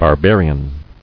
[bar·bar·i·an]